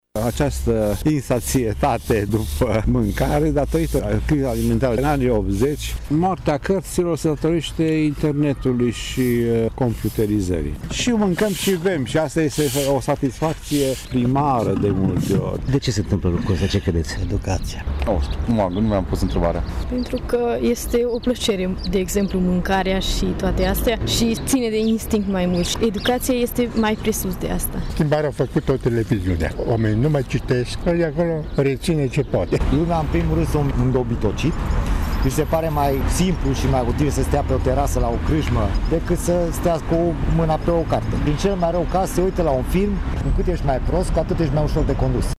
Târgumureșenii cred că o explicație pentru obiceiurile românilor ar fi lipsurile cu care ne-am confruntat de-a lungul timpului:
vali-voxuri.mp3